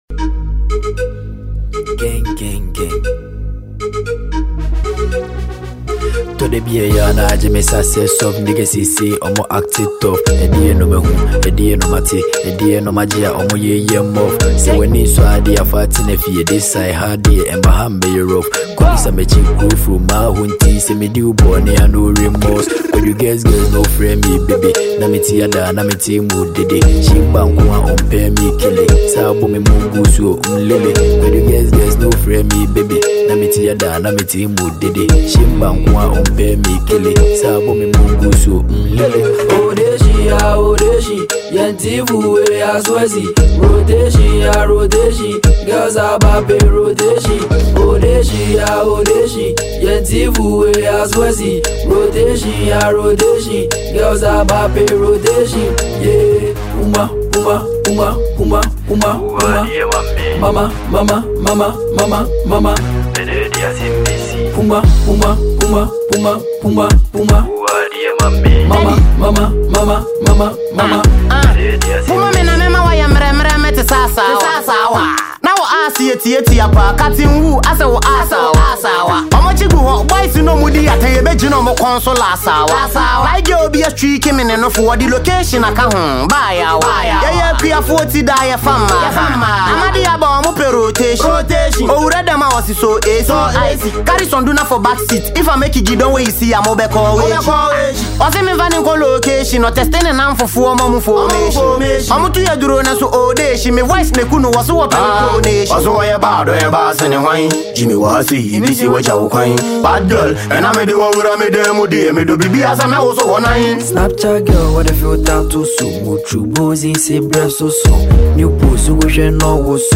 Ghanaian asakaa musician